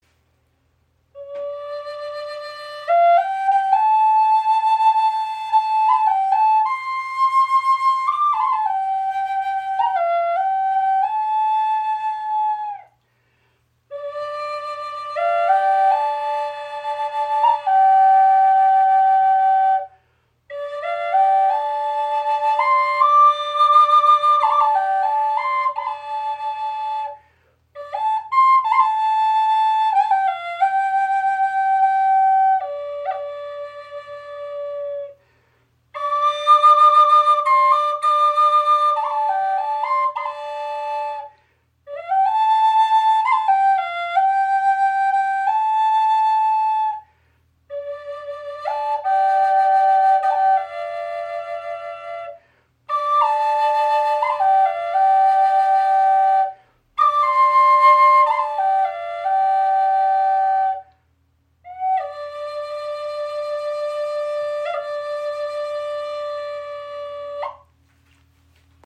Doppelflöte in D in 440 Hz | Schmetterlings Spirit | Teakholz 35 cm
• Icon Gestimmt in D (440 Hz) – klarer, erhebender Doppelflöten-Klang
Ihr Ton in D auf 440 Hz klingt klar, erhebend und zugleich tief berührend. Durch die doppelte Bauweise entsteht ein voller, schwebender Klang, der Dich trägt und mit jedem Atemzug in einen meditativen Fluss führt.